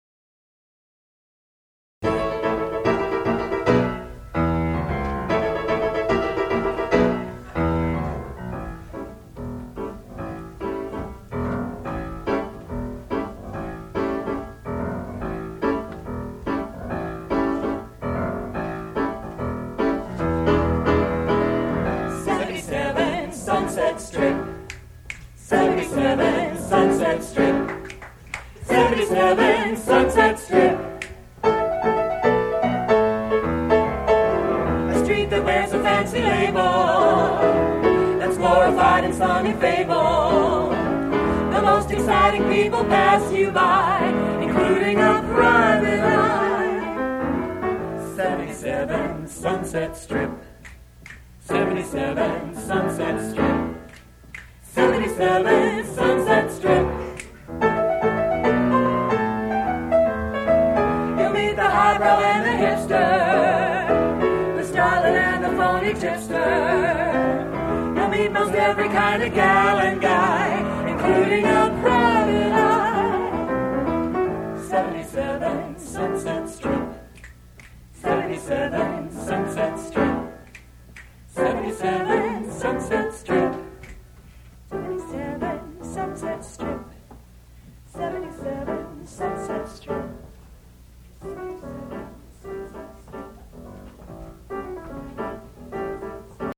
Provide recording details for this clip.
The following songs were recorded live on March 21